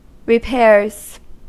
Ääntäminen
Ääntäminen US Haettu sana löytyi näillä lähdekielillä: englanti Käännöksiä ei löytynyt valitulle kohdekielelle. Repairs on sanan repair monikko.